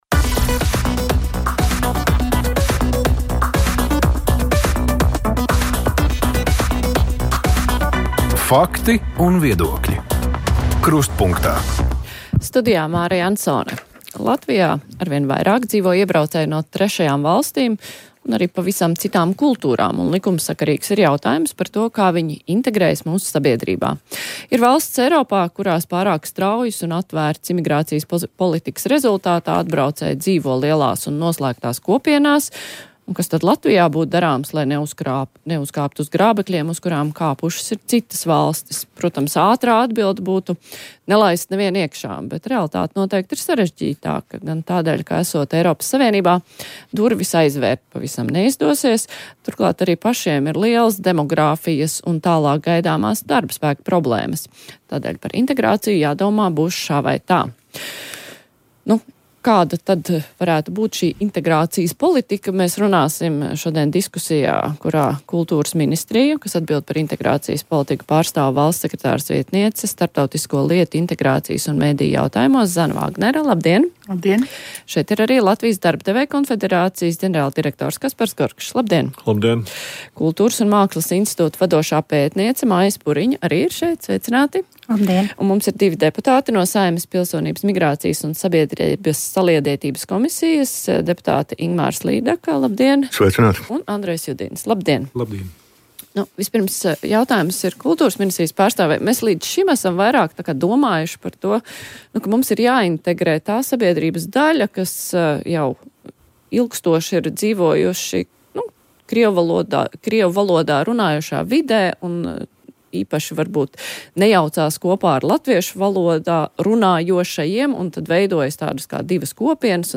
Par to būs diskusija raidījumā Krustpunktā.